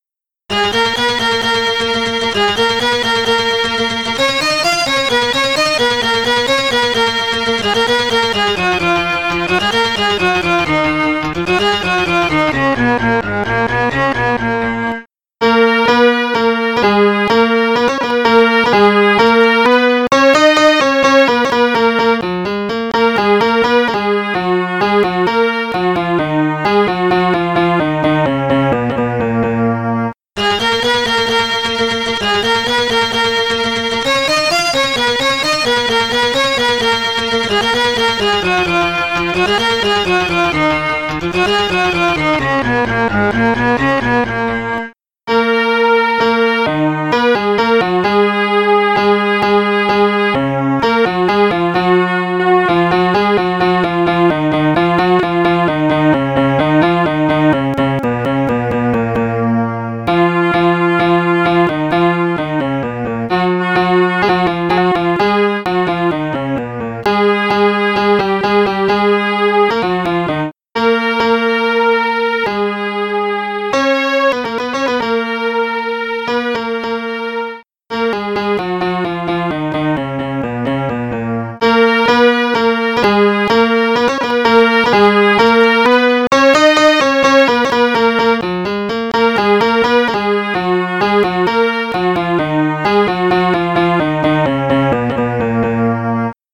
(kurd)